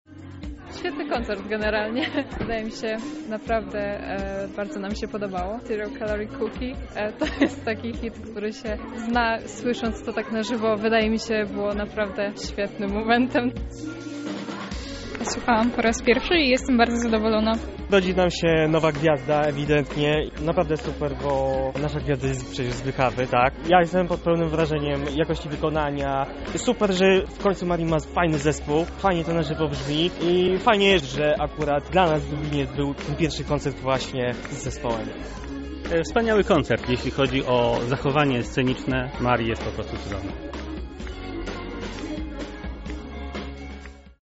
Słuchacze podzielili się swoimi wrażeniami.